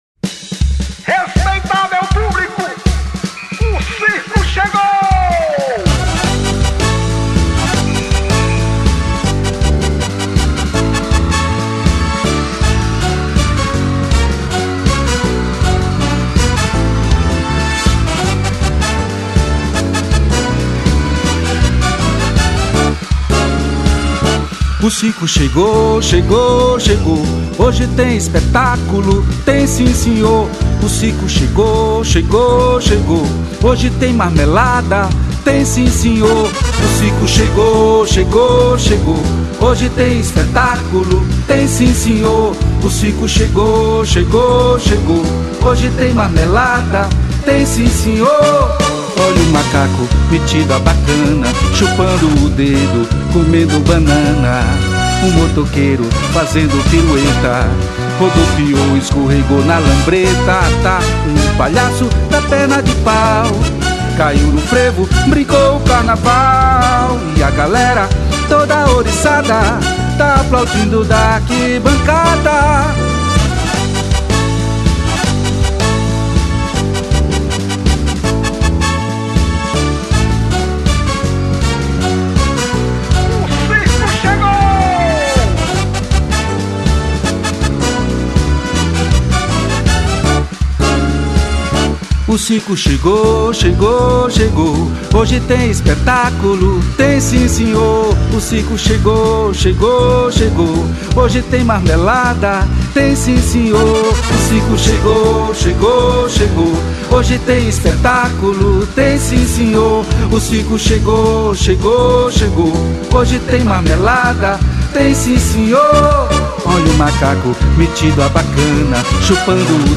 600   02:59:00   Faixa:     Frevo